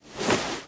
Sfx_creature_babypenguin_hold_letgo_above_01.ogg